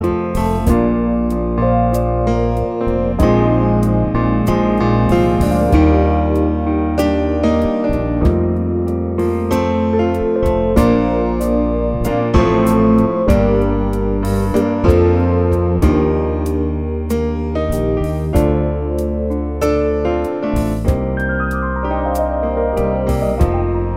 No Electric Or Solo Guitars Duets 4:20 Buy £1.50